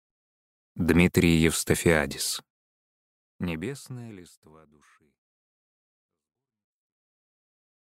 Аудиокнига Небесная листва души. Сборник стихов | Библиотека аудиокниг